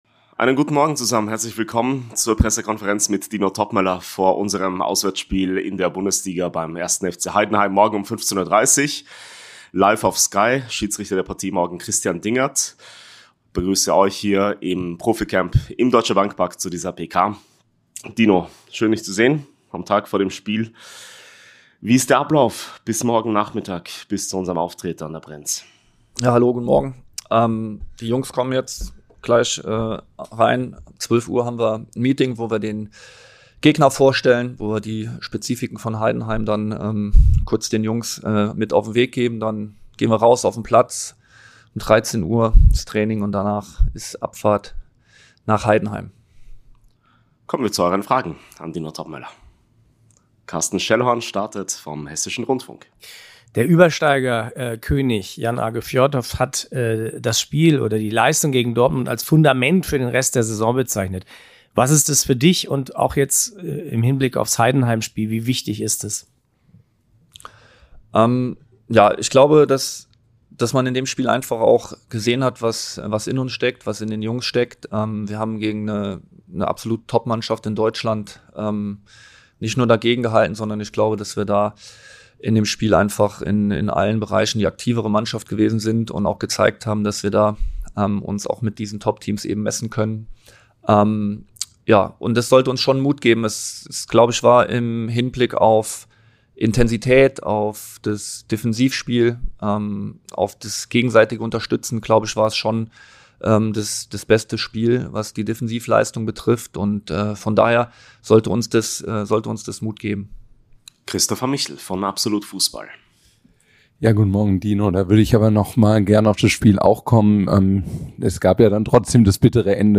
Dino Toppmöller spricht vor dem Aufeinandertreffen gegen den 1. FC Heidenheim auf der Pressekonferenz.